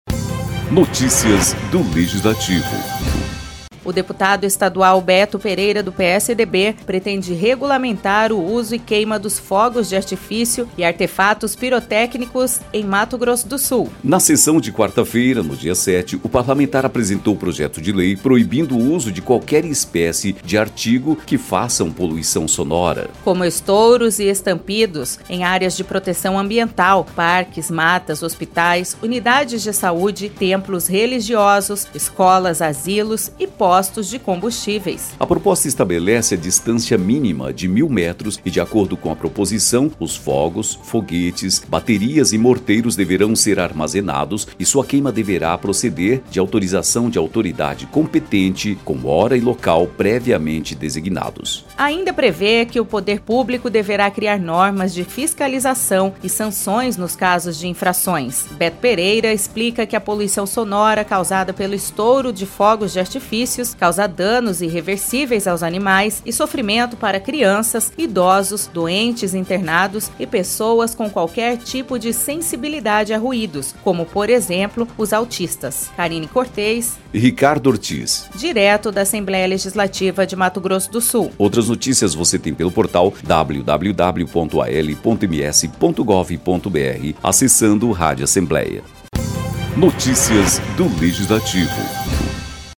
Na tribuna, o deputado defendeu o projeto e registrou que a intenção não é acabar com espetáculos pirotécnicos, eventos e comemorações culturais.